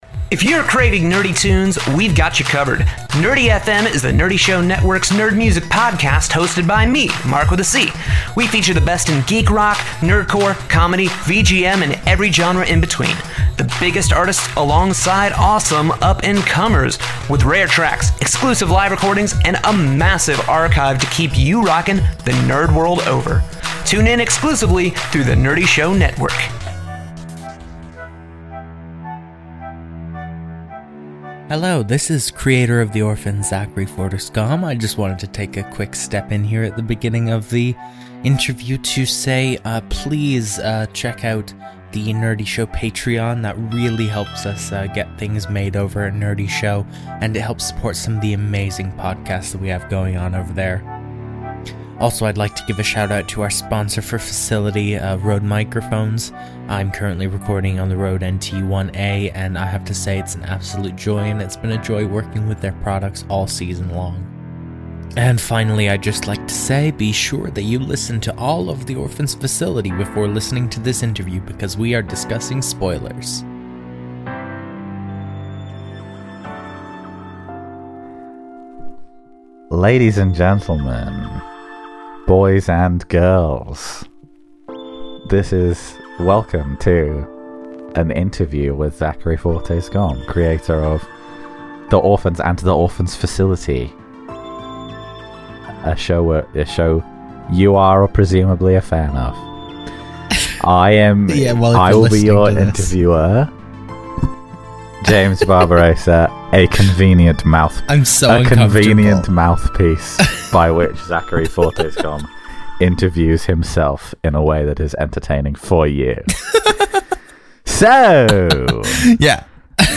Go behind the scenes of The Orphans: Facility with cast and crew interviews leading up to the launch of the next series.